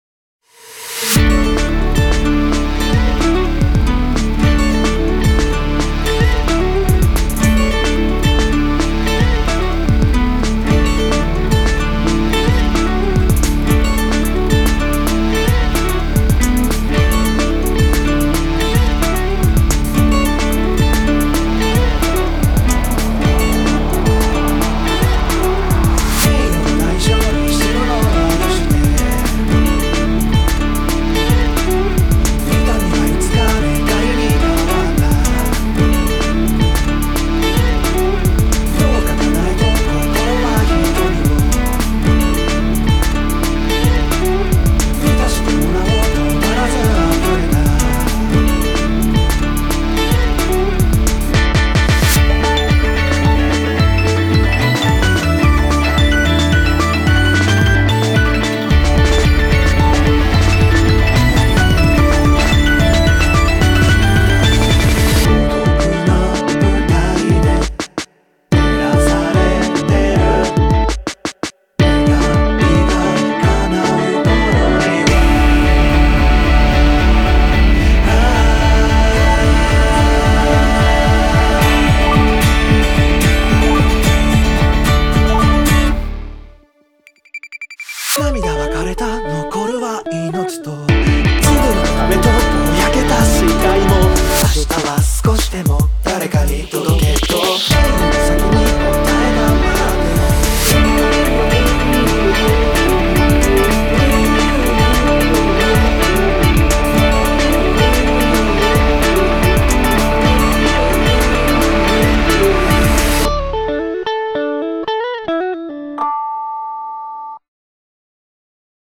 BPM110
Audio QualityPerfect (Low Quality)